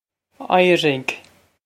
Éirigh Eye-rig
This is an approximate phonetic pronunciation of the phrase.